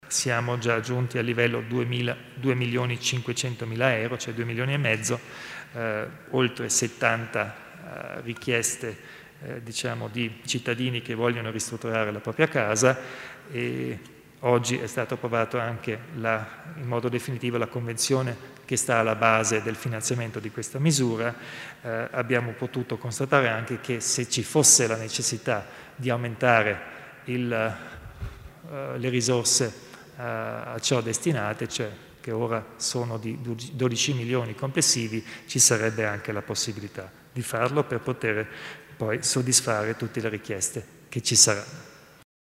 Il Presidente Kompatscher illustra le iniziative in tema di recupero edilizio